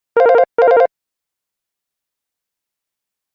nokia-office-tone_24783.mp3